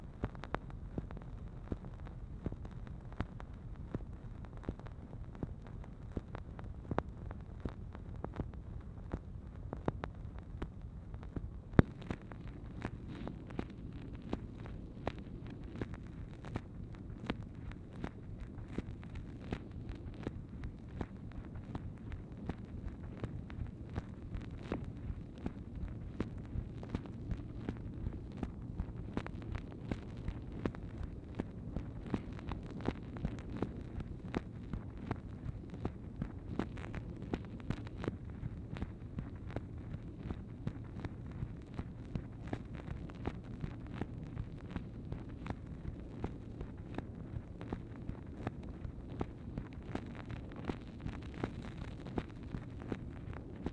MACHINE NOISE, March 30, 1964
Secret White House Tapes | Lyndon B. Johnson Presidency